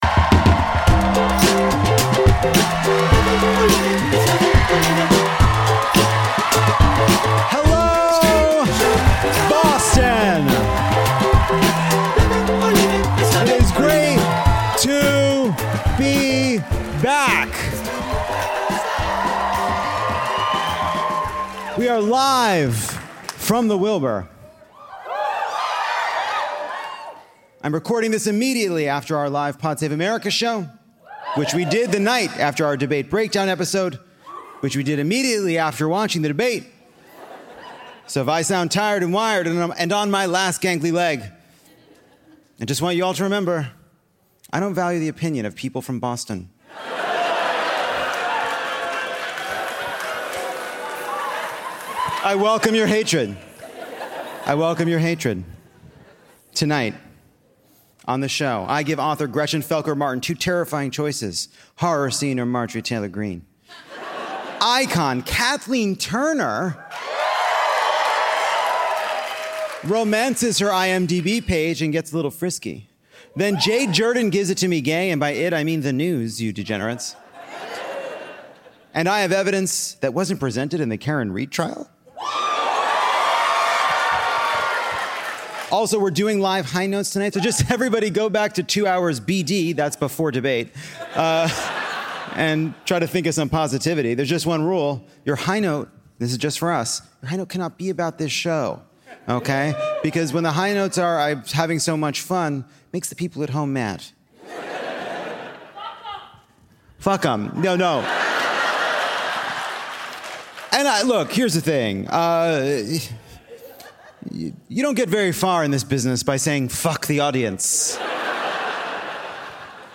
Debait and Switch? (Live from Boston!)
We’ve arrived in Boston and boy are our arms tired!
Kathleen Turner is here and she has STORIES.